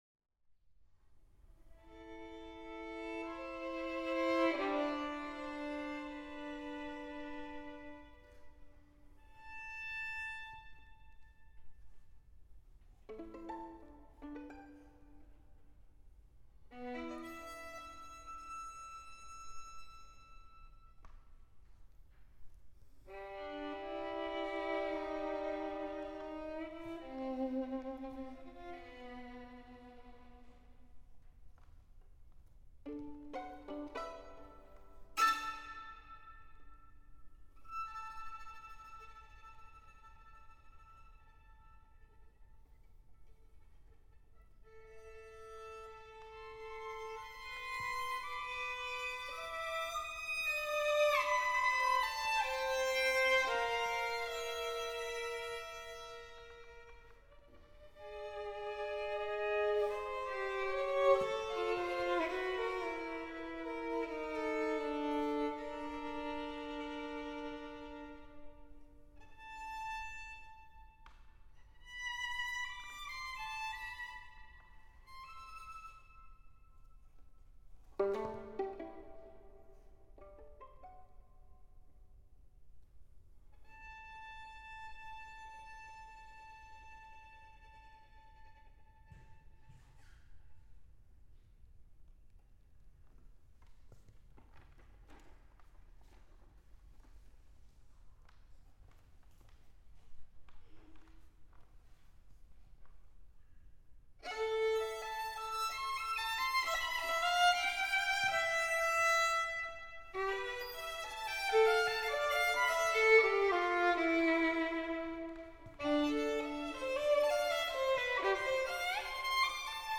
Variations and Distorted Reflections (After Stradella) For solo violin
[thumbnail of Live Performance 240517] Audio (Live Performance 240517)
The multiple movements (twenty-five in total) reflect upon the atmosphere and stylistic character of the Stradella variations with elements of direct and subliminal quotation often using the original melodic contour and/or rhythmic impetus as a starting point, but have been reinterpreted (distorted) and entirely recomposed from a contemporary perspective.